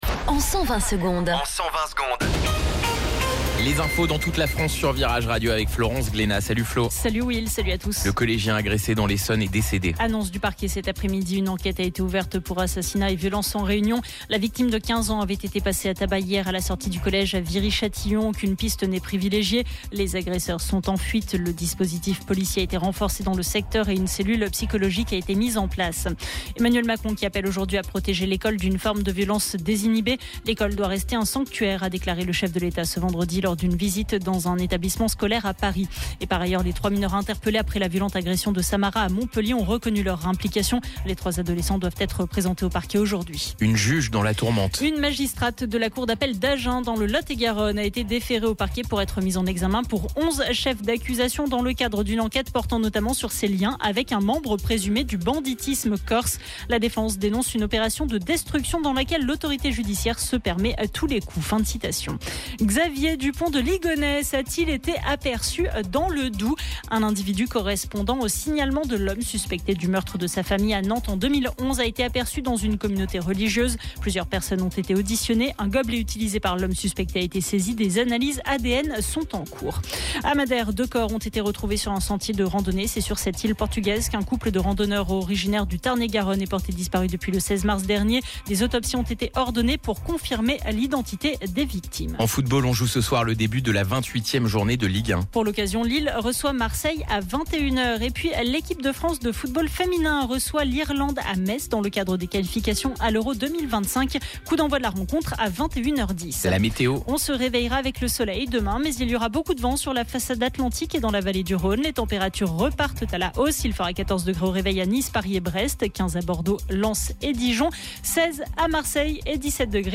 Flash Info National 05 Avril 2024 Du 05/04/2024 à 17h10 Flash Info Télécharger le podcast Partager : À découvrir Oasis à la rescousse de New Order ?